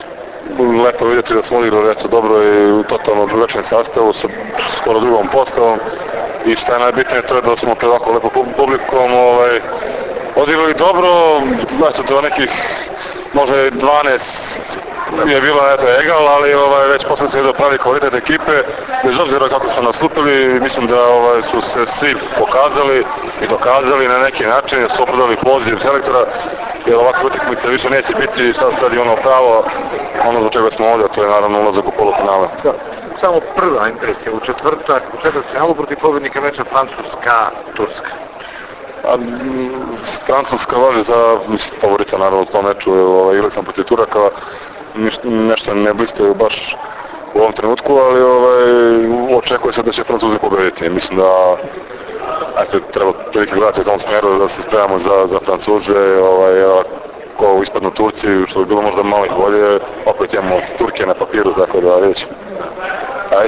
IZJAVA VLADE PETKOVIĆA